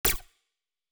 mixkit-short-laser-gun-shot-1670.wav